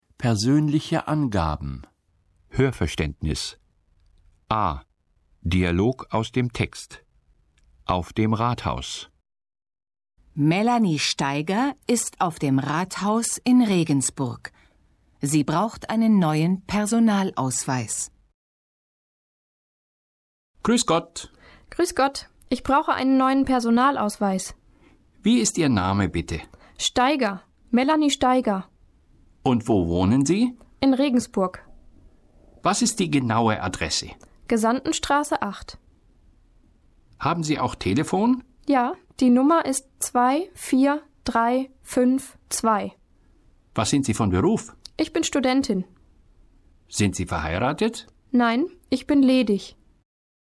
Dialog aus dem Text: Auf dem Rathaus (809.0K)